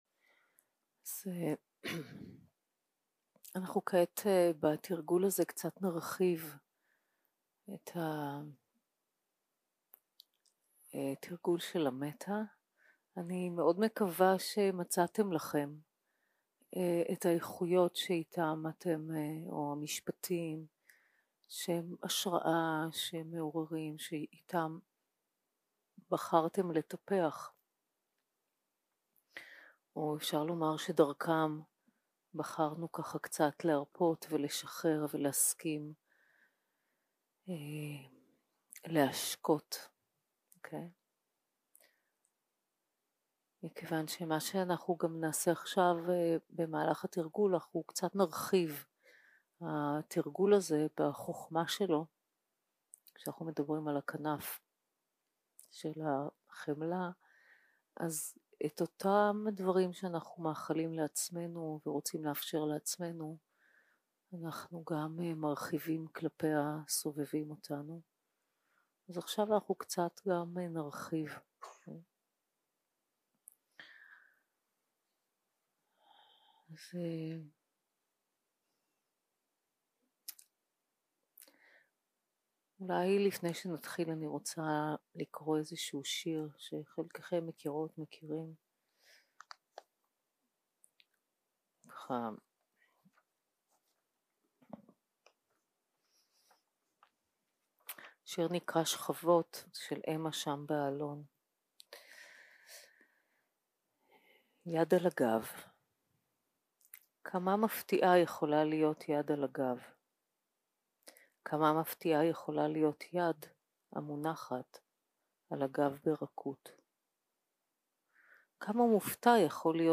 יום 5 - הקלטה 12 - צהרים - מדיטציה מונחית - מטא לעצמי ולדמות המיטיבה 0:00 0:00 סוג ההקלטה: Dharma type: Guided meditation שפת ההקלטה: Dharma talk language: Hebrew